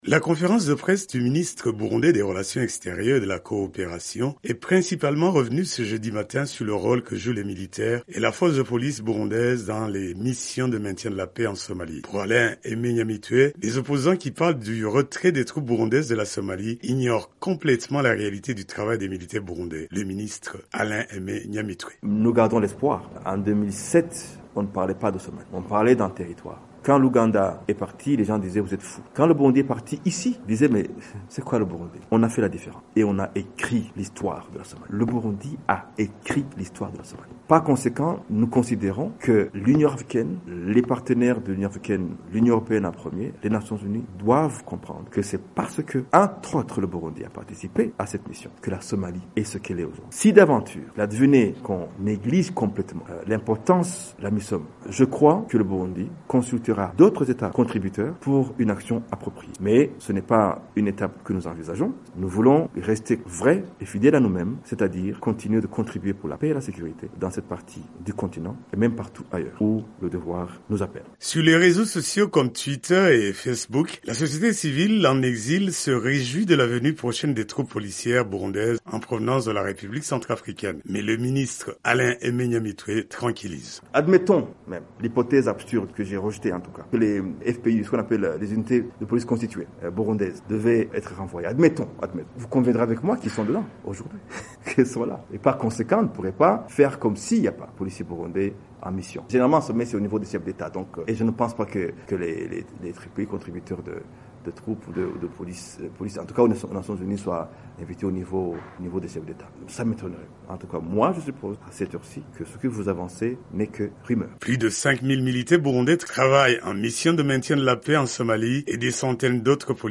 Lors d'une conférence de presse, le ministre des relations extérieures, Alain Aimé Nyamitwe, a réaffirmé l'importance de la présence du Burundi en Somalie.